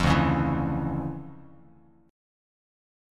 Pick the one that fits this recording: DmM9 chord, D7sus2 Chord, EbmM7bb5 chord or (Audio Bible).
DmM9 chord